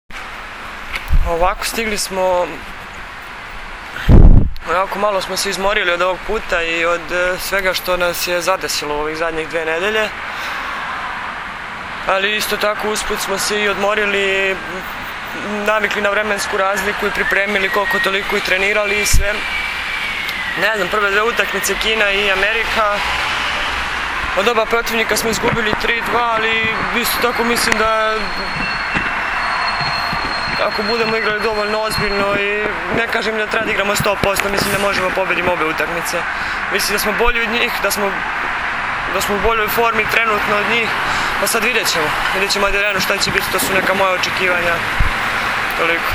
IZJAVA NATAŠE KRSMANOVIĆ